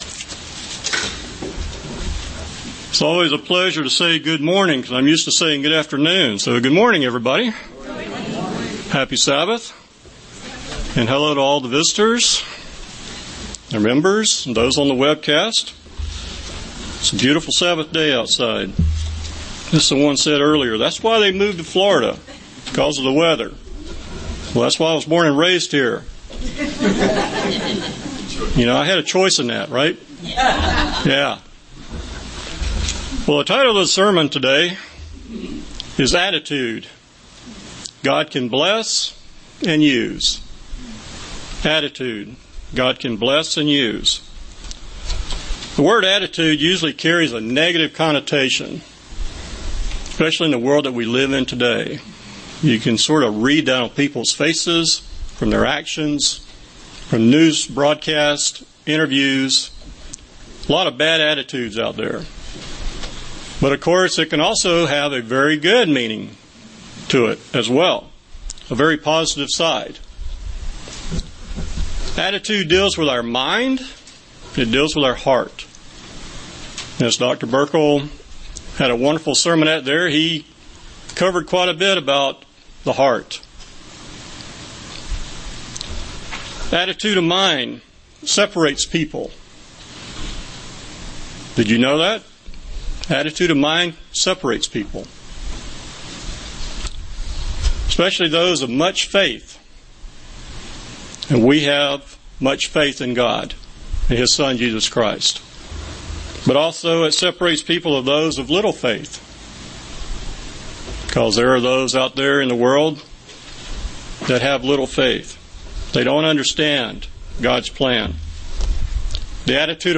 UCG Sermon Studying the bible?
Given in St. Petersburg, FL